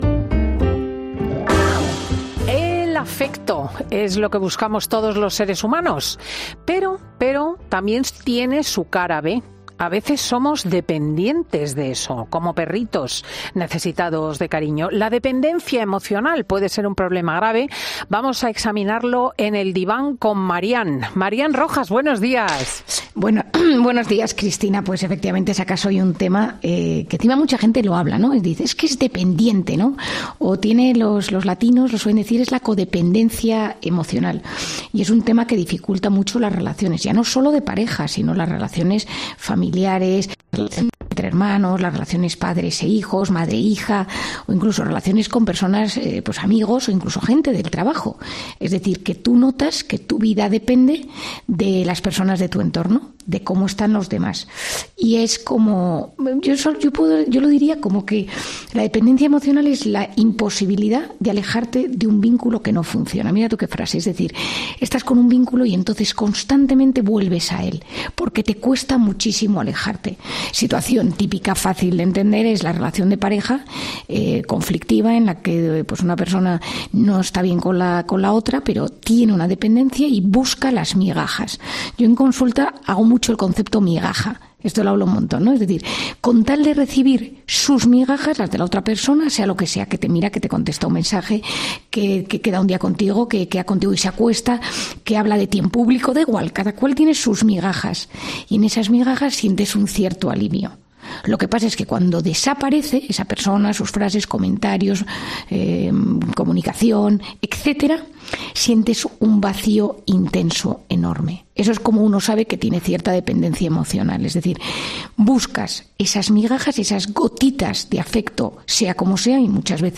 La psiquiatra explica en 'Fin de Semana' en qué consiste y qué debemos hacer para evitar somatizar